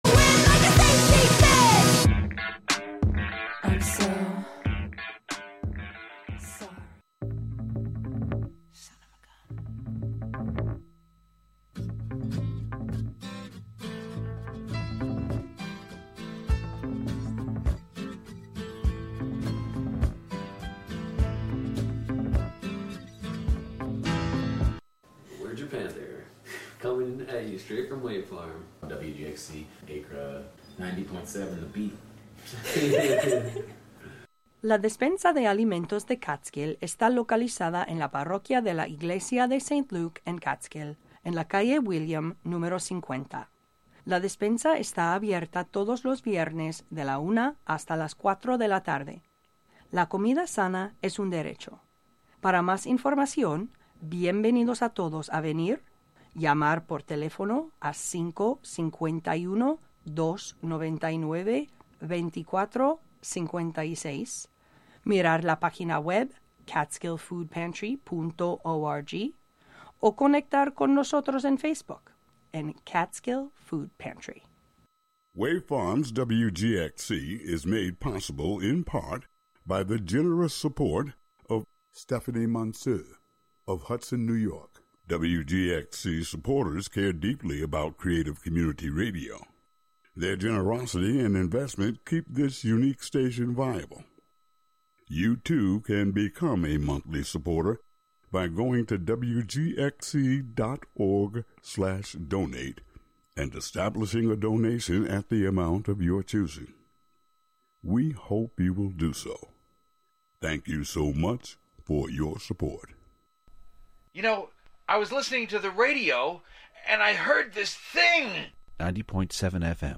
11am Live from Brooklyn, New York
making instant techno 90 percent of the time